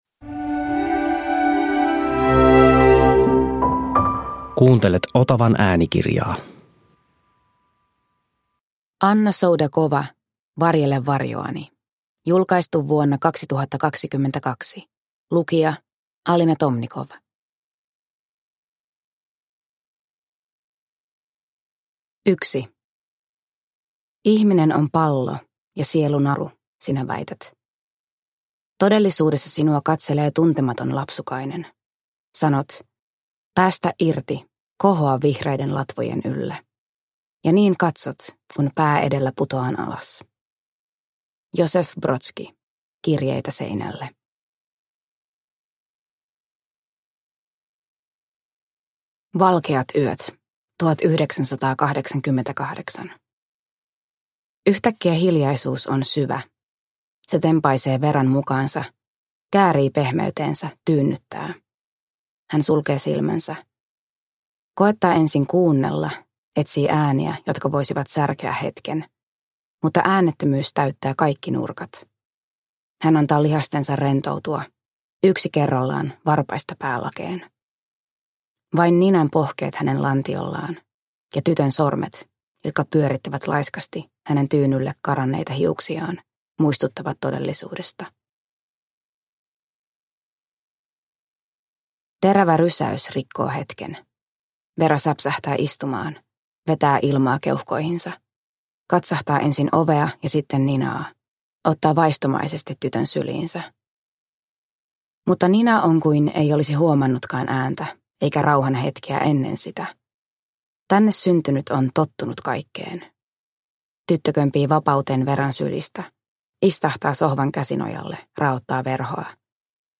Varjele varjoani – Ljudbok – Laddas ner